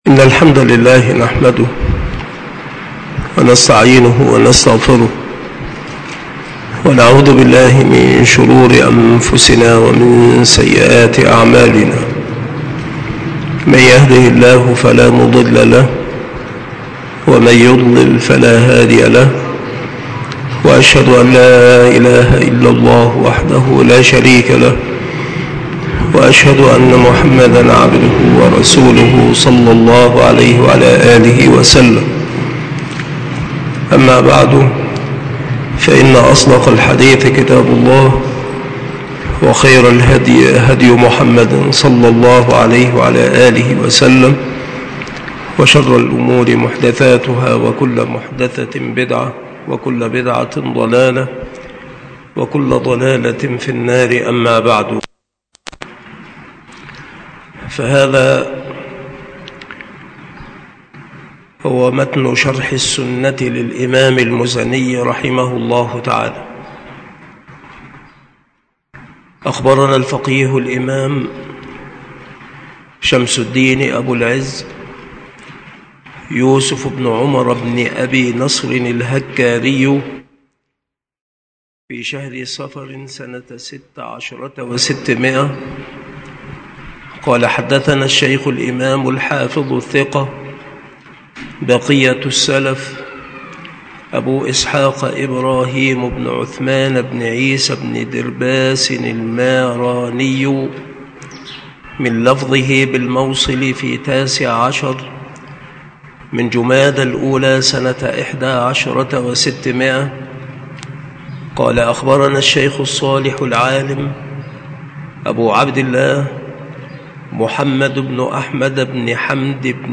مكان إلقاء هذه المحاضرة بالمسجد الشرقي بسبك الأحد - أشمون - محافظة المنوفية - مصر عناصر المحاضرة : متن شرح السنة للإمام المُزَنِي - رحمه الله تعالى -.